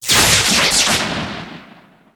energy.wav